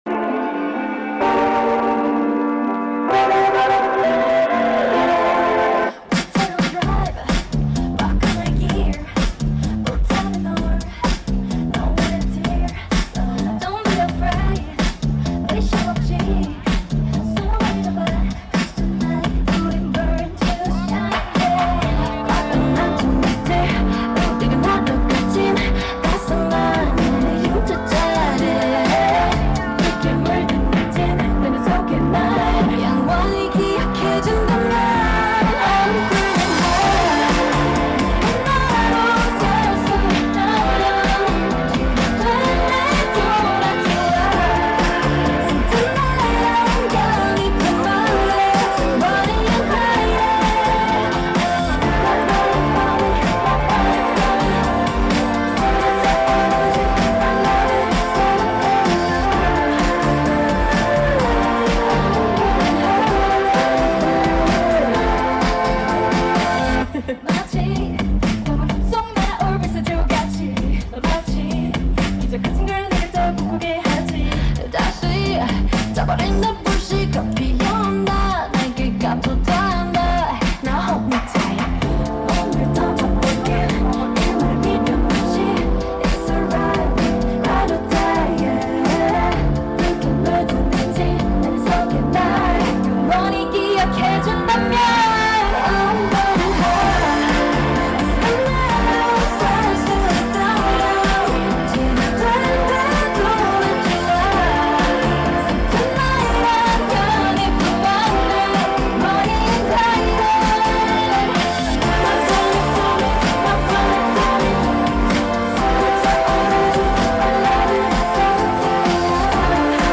Korea University Festival